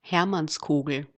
The Hermannskogel (German pronunciation: [ˈhɛʁmansˌkoːɡl̩]
De-at_Hermannskogel.ogg.mp3